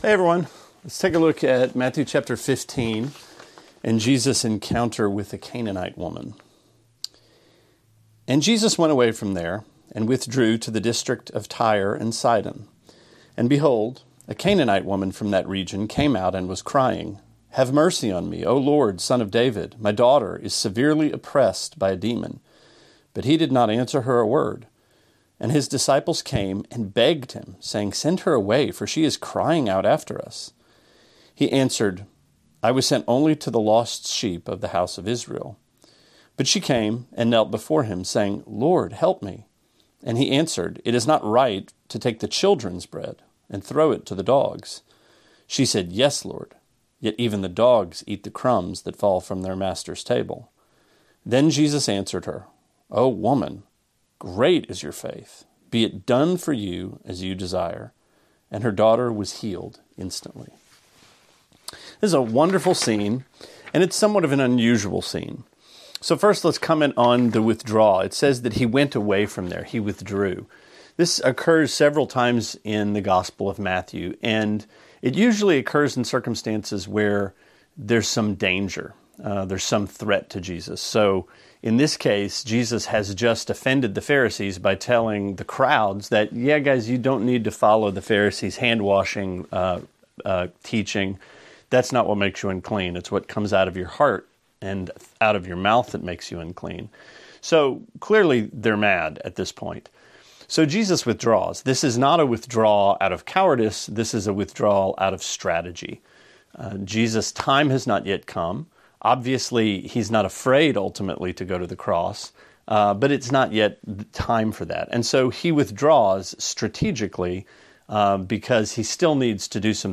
Sermonette 3/3: Matthew 15:21-28: Scraps for the Dogs – Trinity Christian Fellowship